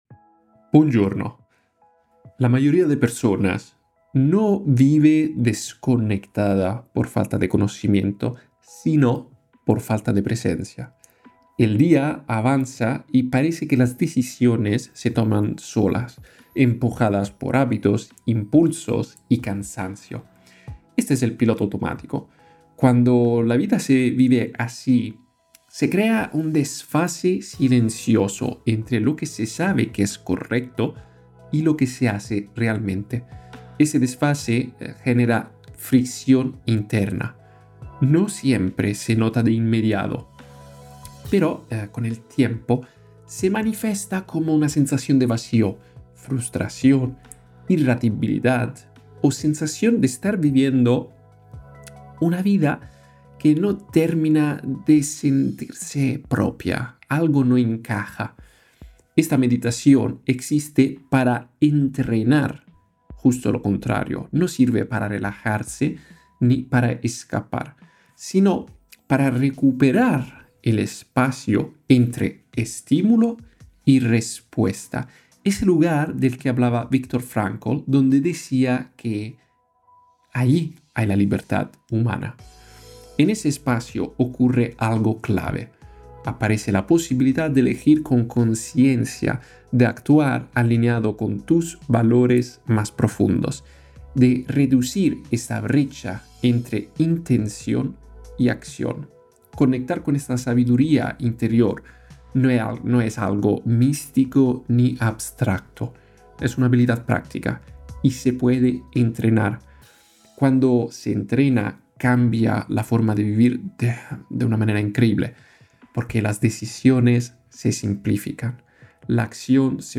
Meditación guiada para salir del piloto automático, actuar con conciencia y vivir con sabiduría, presencia y carácter. Incluye una práctica diaria de 15 segundos.